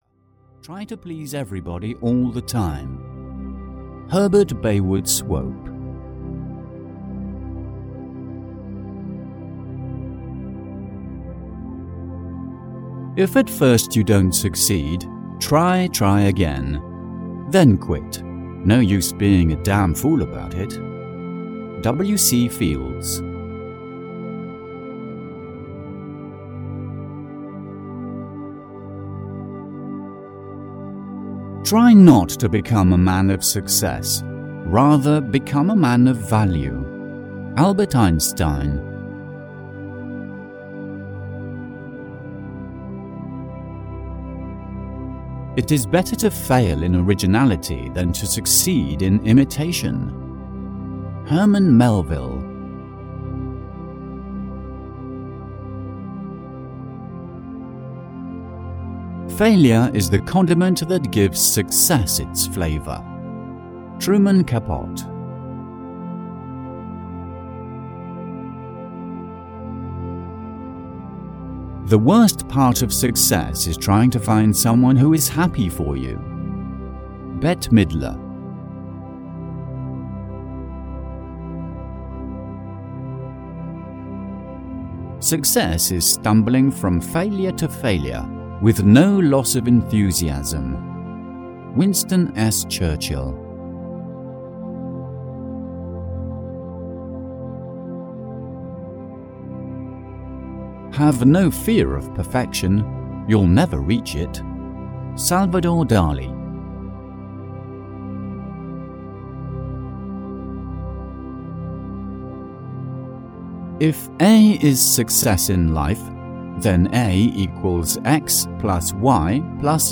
100 Quotes About Success (EN) audiokniha
Ukázka z knihy